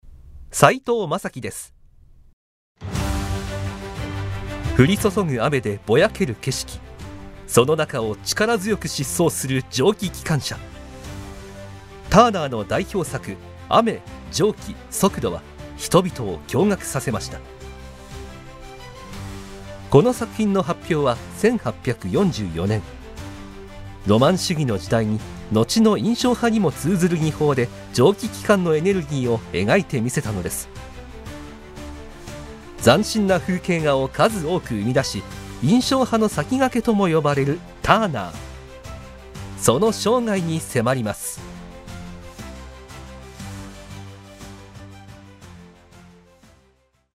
ボイスサンプル
• 声ににじみ出る優しさ
• 音域：高～中音
• 声の特徴：さわやか、優しい